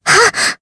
Jane-Vox_Attack2_jp.wav